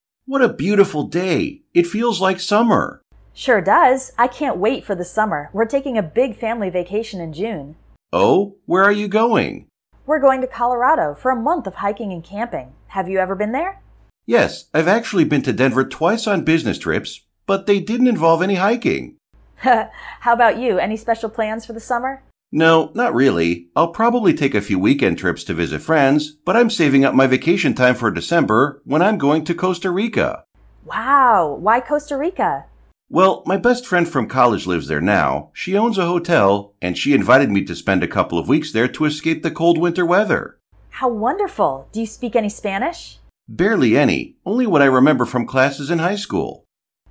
Example English Conversations
Conversation 2: